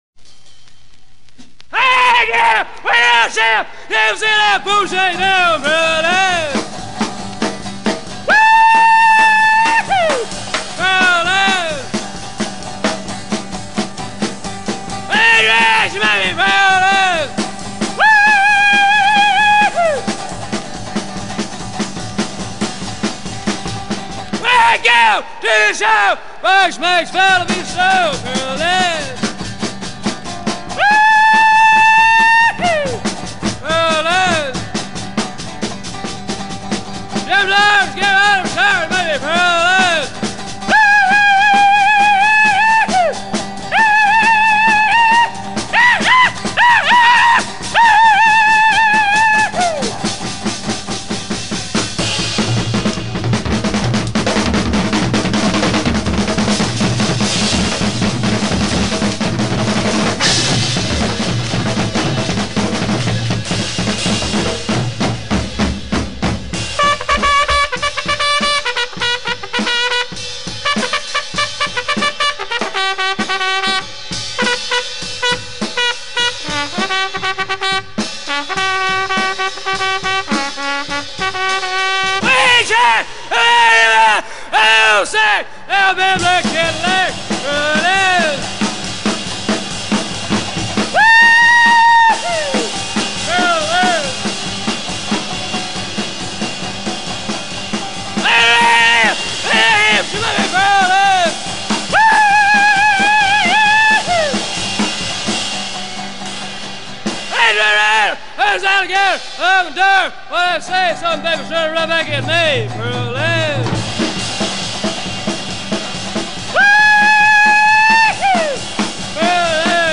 один из родоначальников сайкобилли